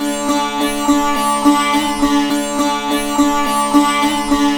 105-SITAR1-R.wav